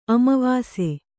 ♪ amavāse